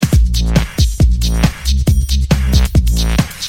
dooms_night_loop.mp3